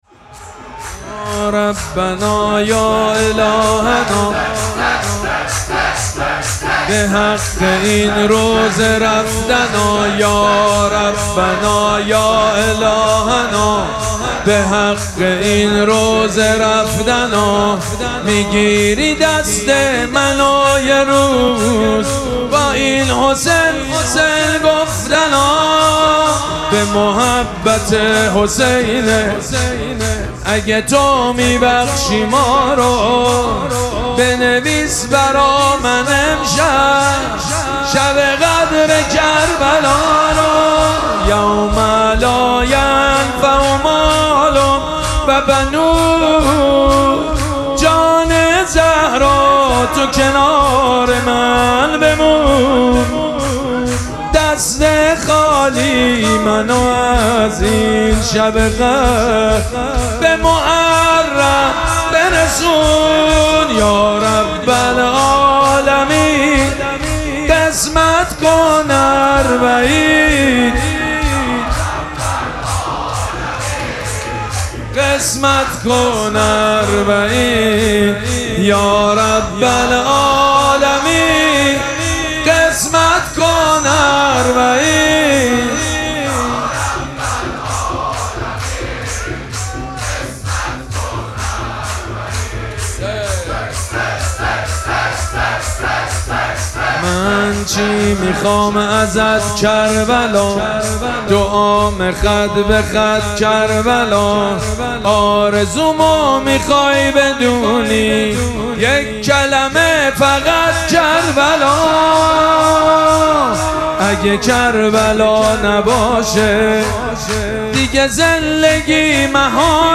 مراسم مناجات شب بیست و یکم ماه مبارک رمضان
شور
مداح
حاج سید مجید بنی فاطمه